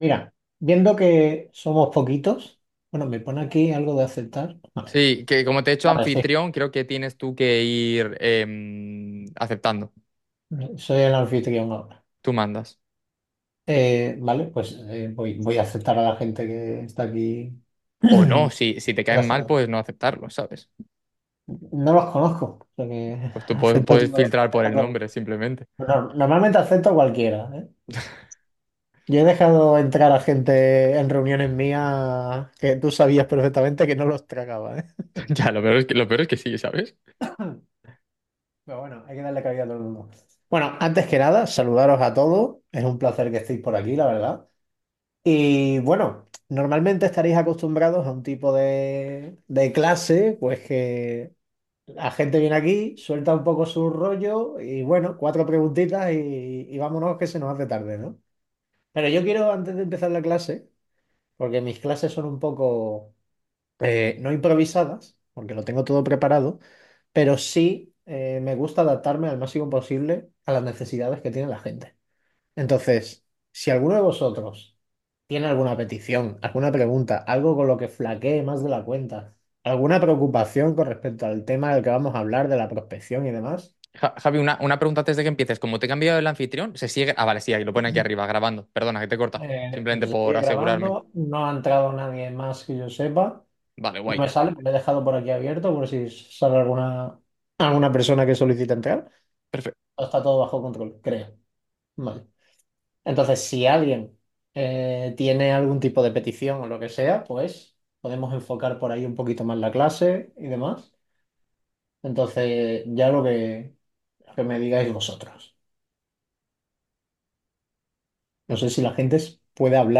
Masterclass | Prospección por Linkedin